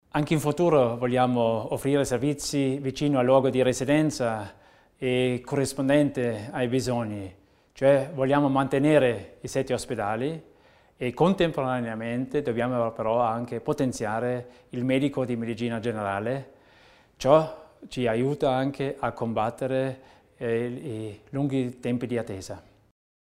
L'Assessore Theiner spiega gli obiettivi in ambito sanitario